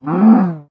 mob / cow / hurt2.ogg
hurt2.ogg